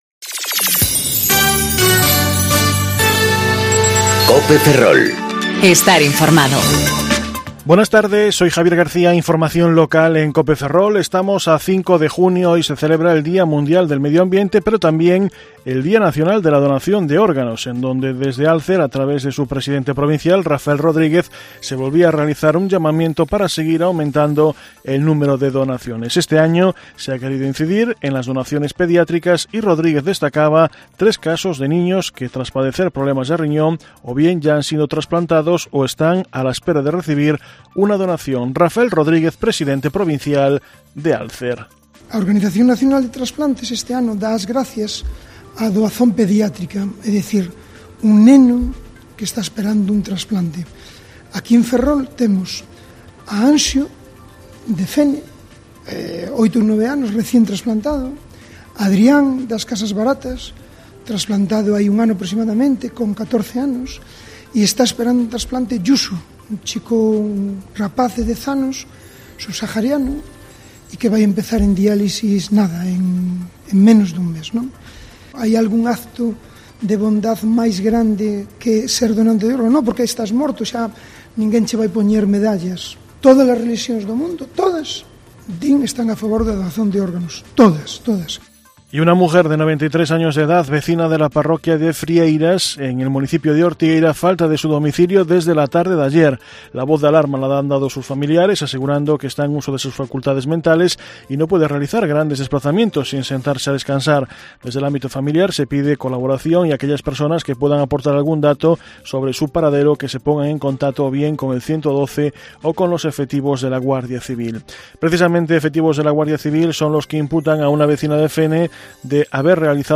Informativo Mediodía Cope Ferrol 5/06/2019 (De 14.20 a 14.30 horas)